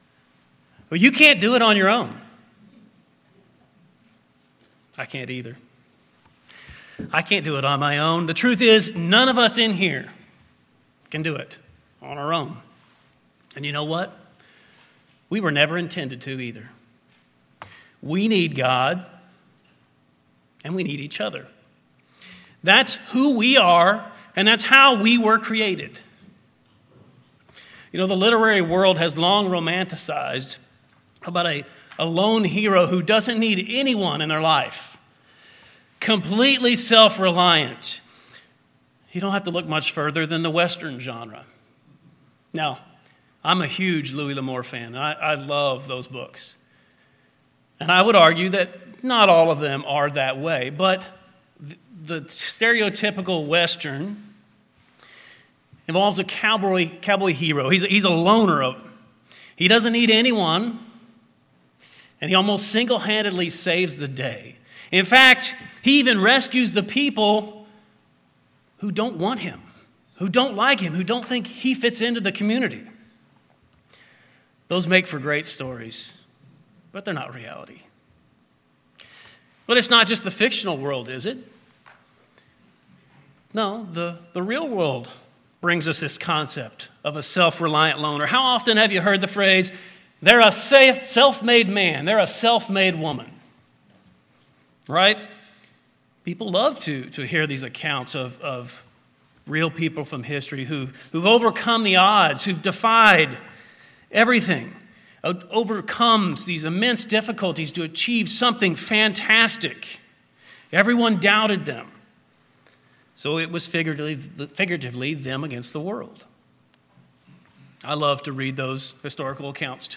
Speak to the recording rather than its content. Given in East Texas